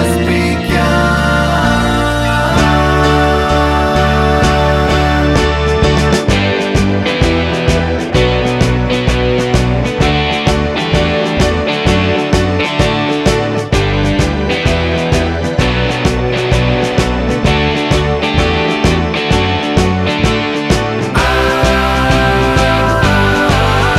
Two Semitones Down Glam Rock 3:43 Buy £1.50